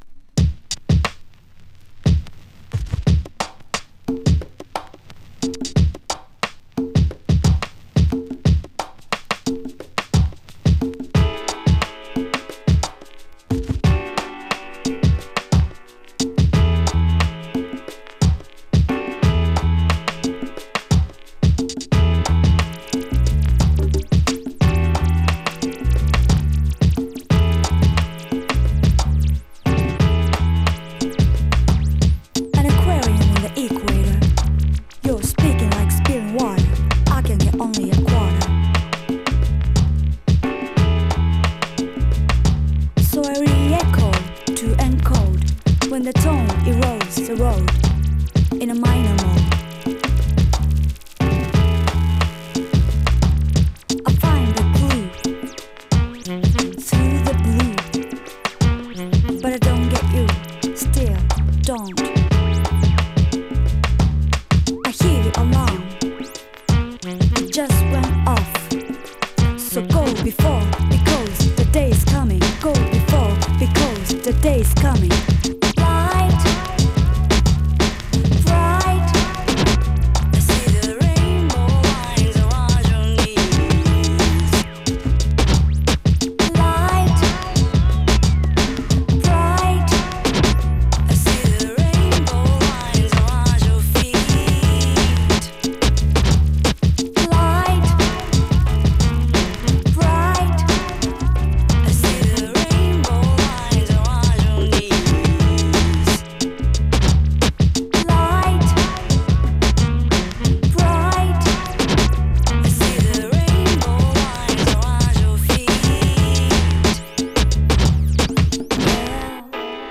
ソリッドなリズムの新しくもどこか懐かしいポップ・ミュージック。